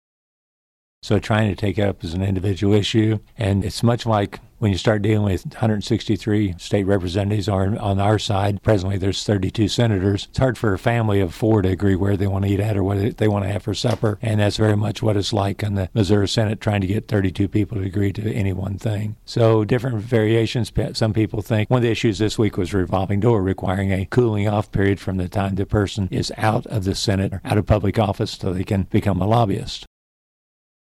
Senator Cunningham says two of six ethics bills were debated in the Missouri Senate this week.